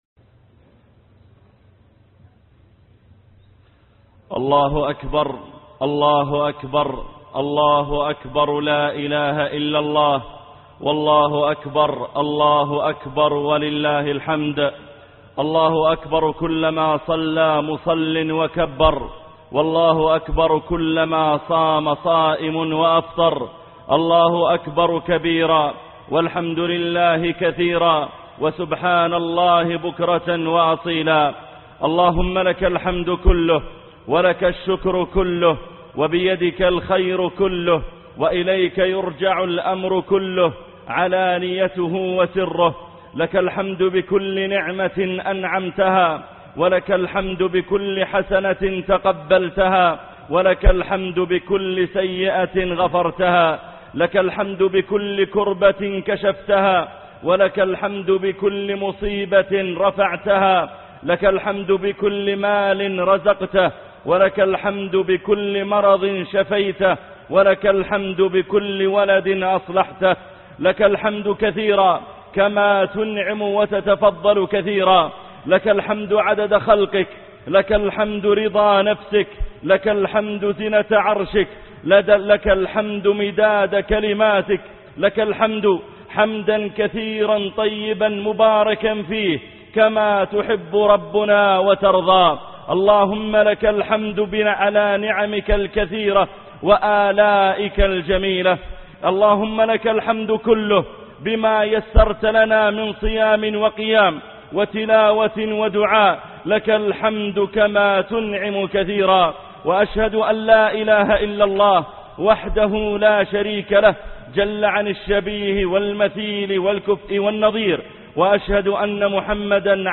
خطبة عيد الفطر لعام 1432 هـ - خطب الجمعة - الشيخ محمد العريفي